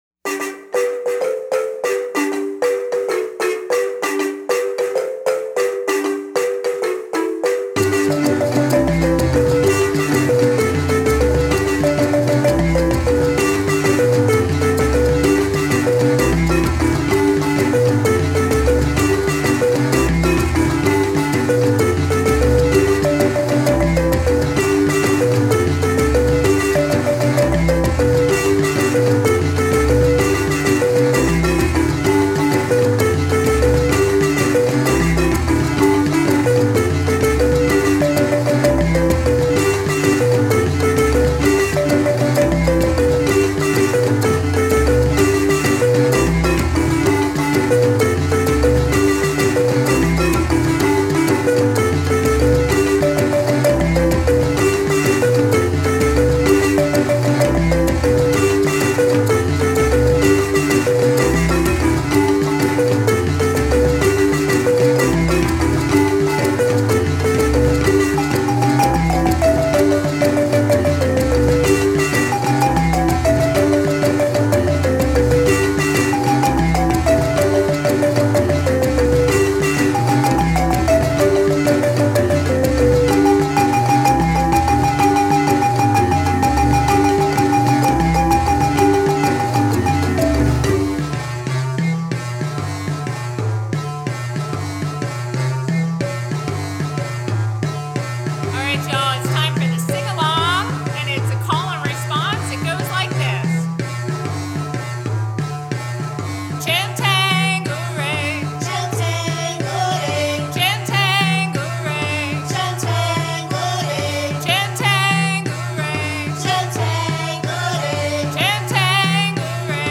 Zimbabwean marimba